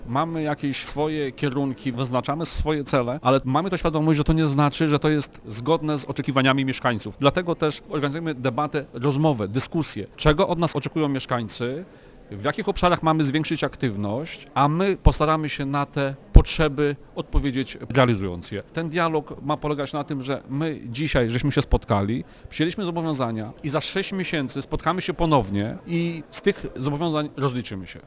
W Starostwie Powiatowym w Lublinie, w środę 30 października, odbyła się debata poświęcona bezpieczeństwu mieszkańców.
Komendant Miejski Policji w Lublinie inspektor Dariusz Szkodziński podkreślił, że informacje, przekazane podczas środowej debaty są dla funkcjonariuszy bardzo cenne i zapewnił, że zgłoszone postulaty będą realizowane: